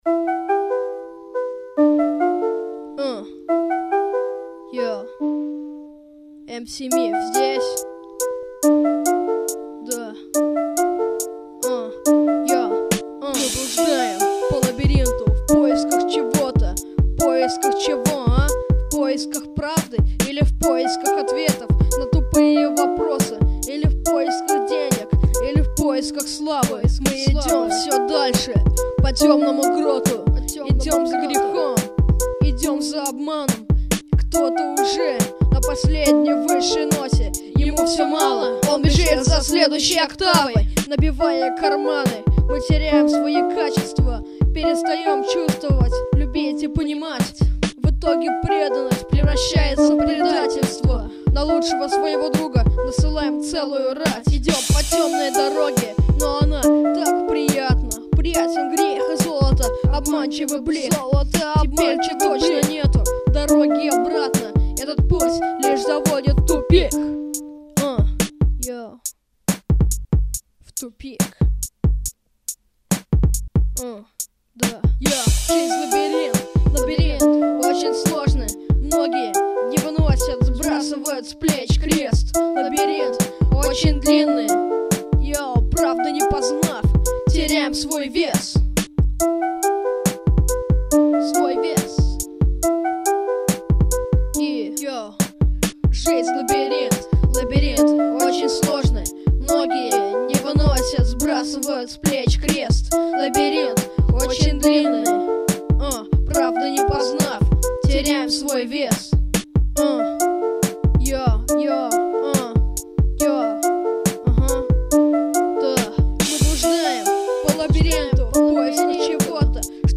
mp3,3650k] Рэп